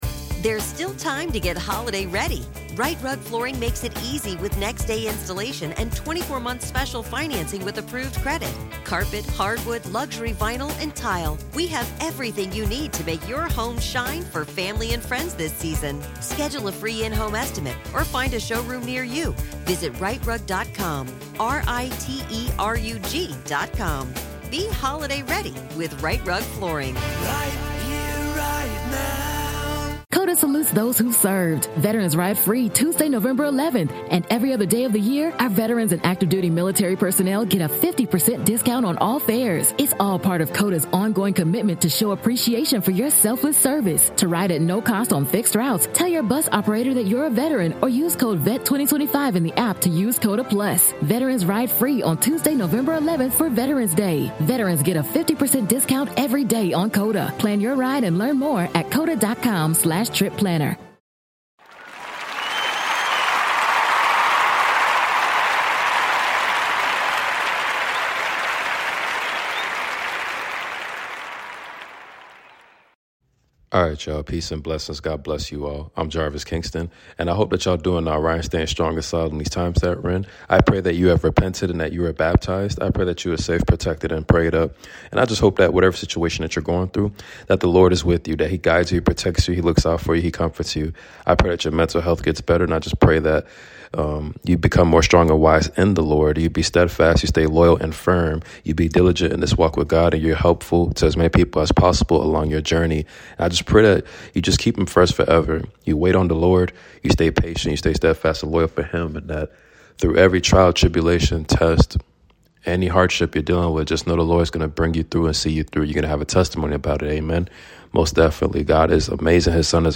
Reading from the booklet.